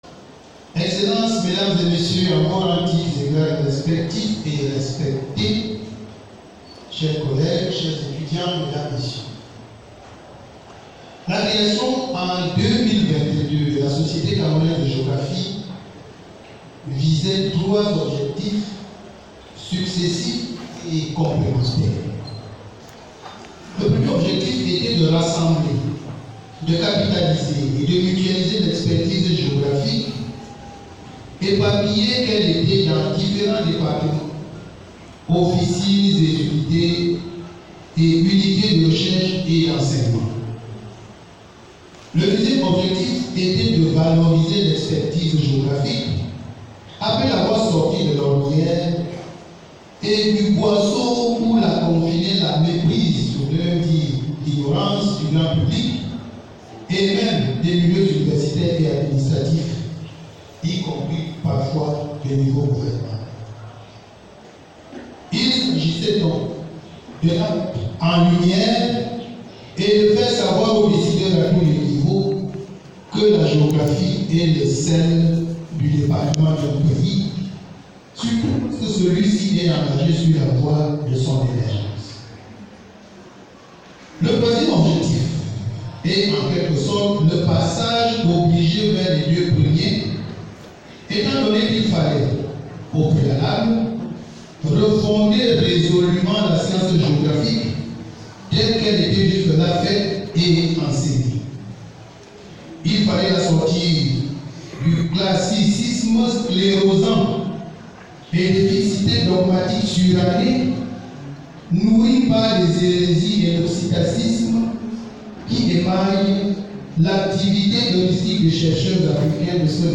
Discours